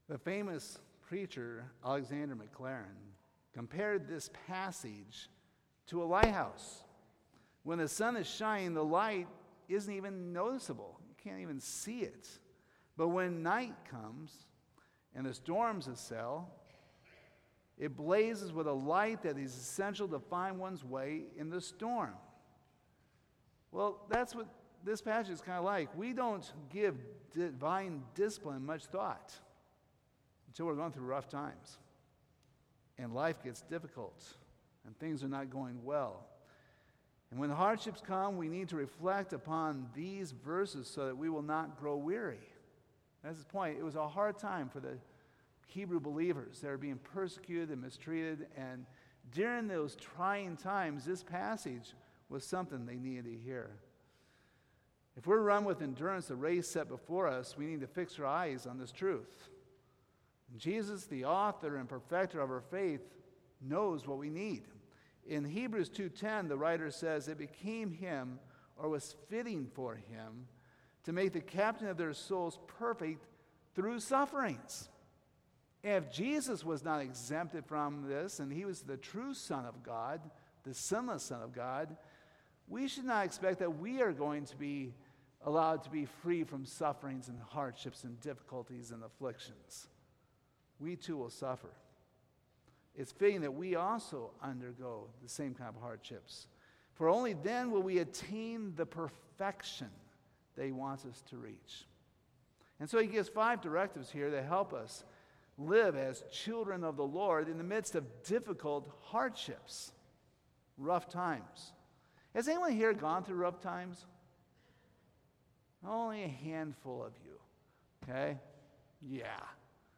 Hebrews 12:4-13 Service Type: Sunday Morning This is a convicting passage.